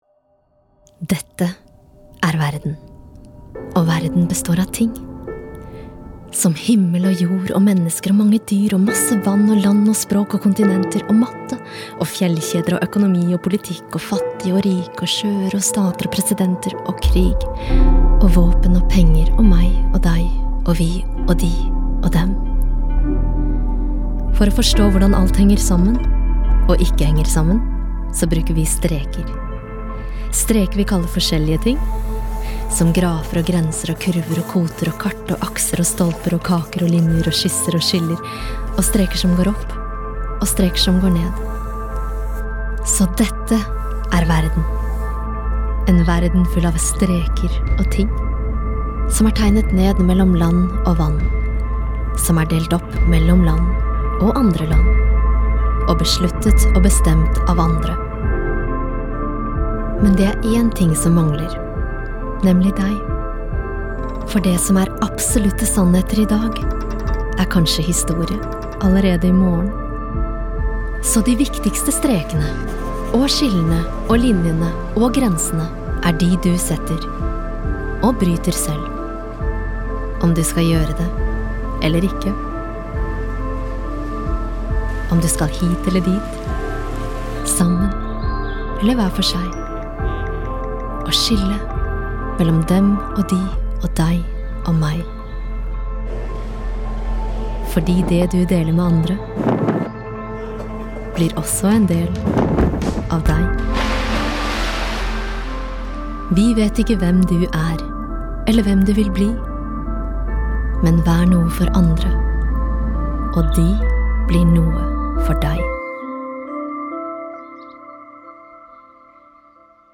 Female
Friendly, Confident, Character, Corporate, Energetic, Natural, Warm, Engaging
dry studio read.mp3
Microphone: SM Pro Audio MC01